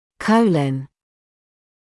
[‘kəulɔn][‘коулон]ободочная кишка; толстая кишка